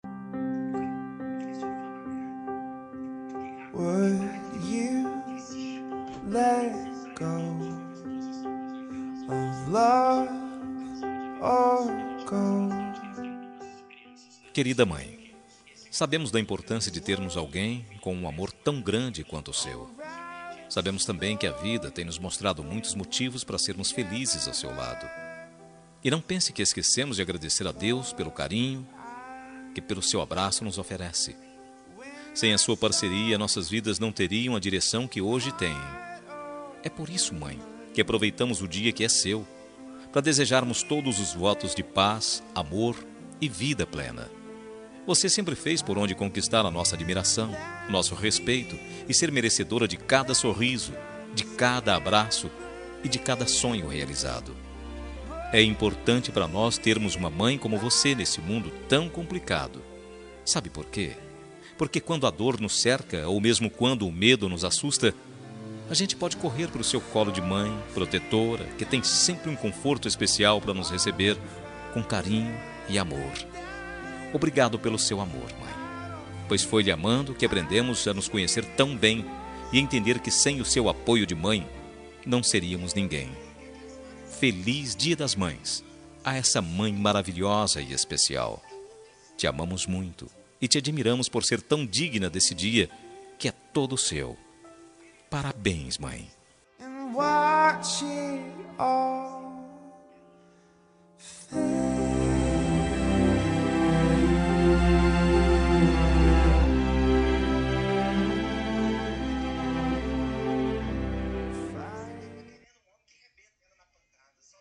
Dia das Mães – Para minha Mãe – Voz Masculina – Plural – Cód: 6527